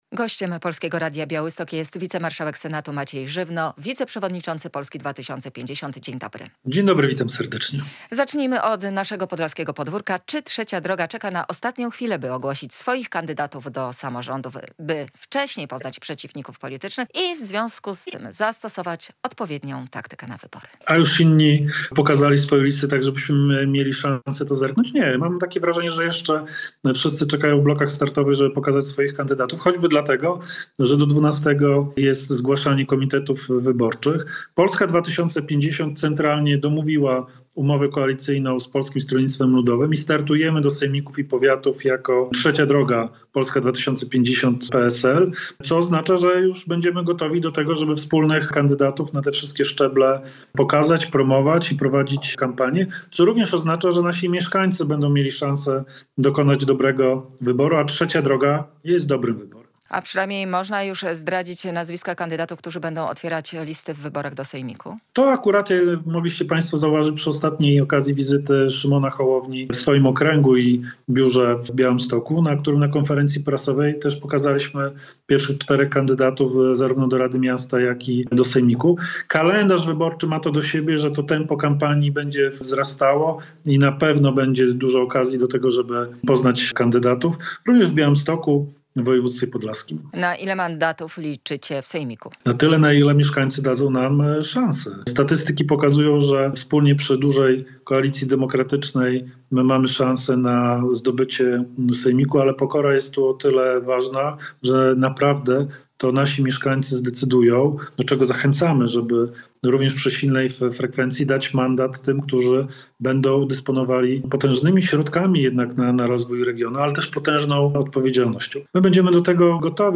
Radio Białystok | Gość | Maciej Żywno [wideo] - wicemarszałek Senatu
studio